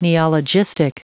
Prononciation du mot neologistic en anglais (fichier audio)
Prononciation du mot : neologistic